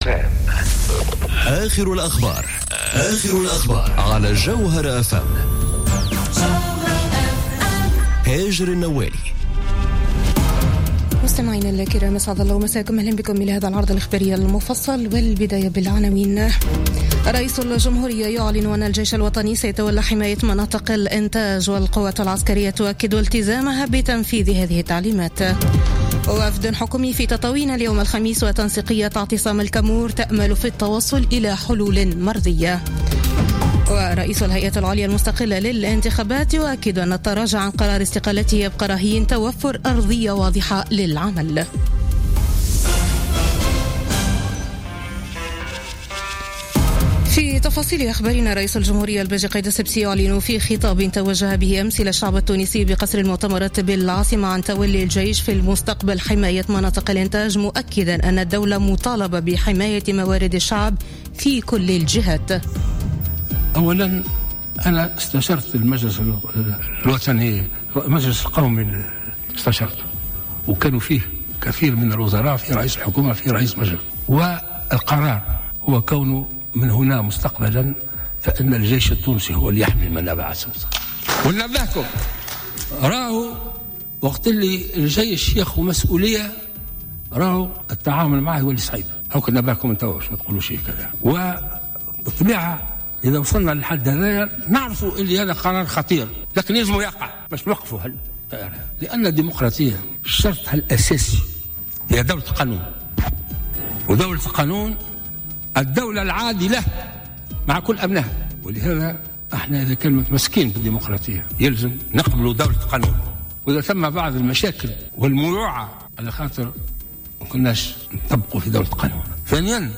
نشرة اخبار منتصف الليل ليوم الخميس 11 ماي 2017